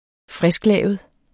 Udtale [ -ˌlæˀvəð ]